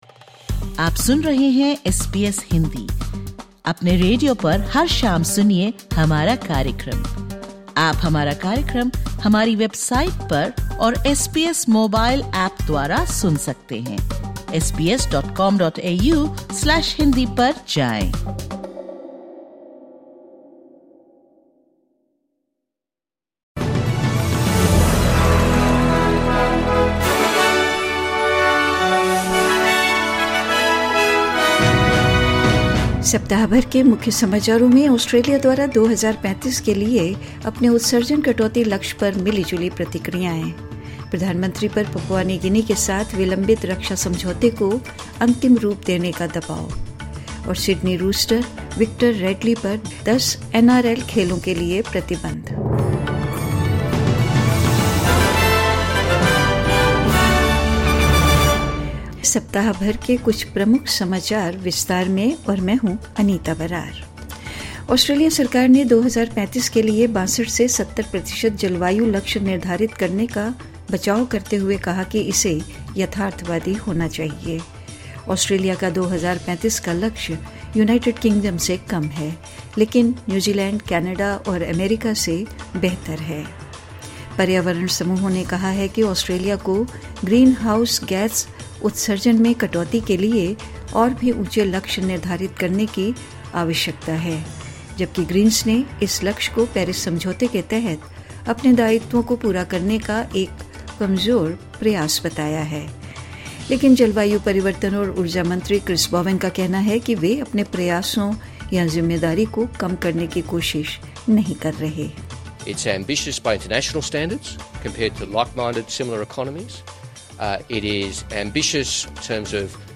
साप्ताहिक समाचार 20 सितम्बर 2025
सुनें सप्ताह भर के समाचार